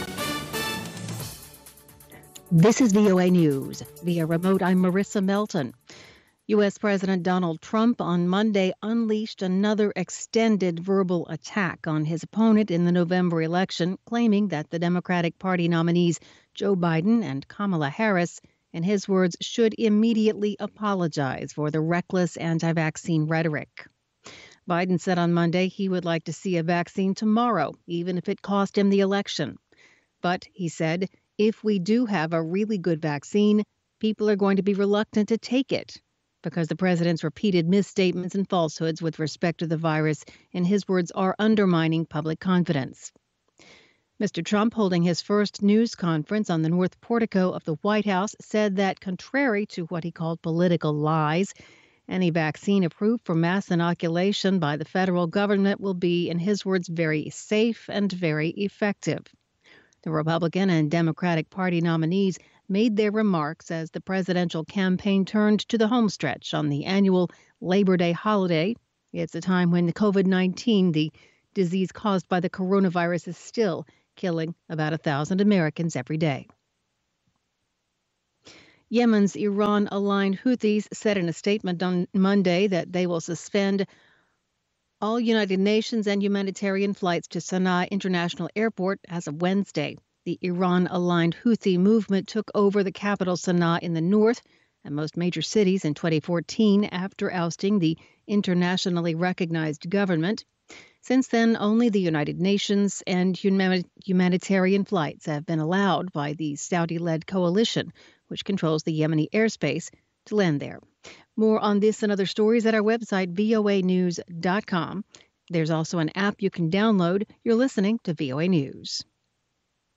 VOAのラジオニュースの音声をナラボー・プレスが書き起こしました。